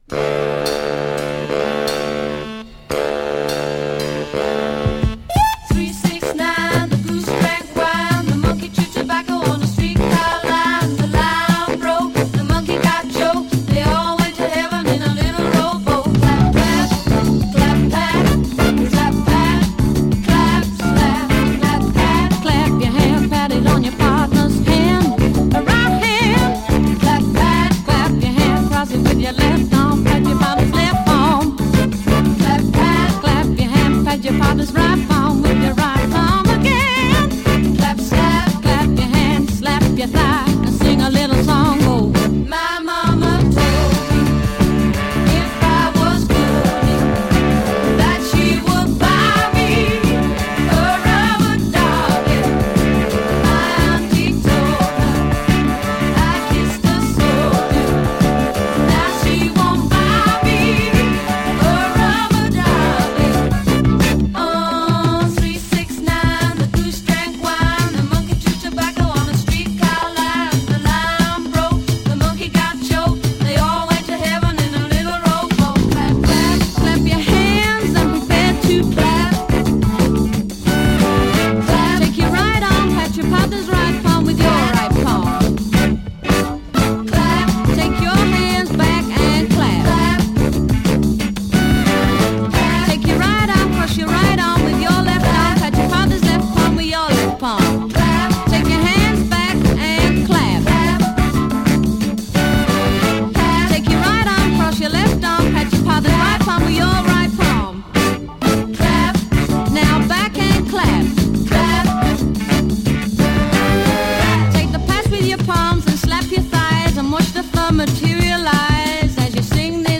format: 7inch
USガールズ・コーラス・グループ
ブラスを効かせた跳ねるビートにキュートなコーラスも映える、原曲に負けず劣らずのナイス・カヴァーです♪ ドイツ・プレス。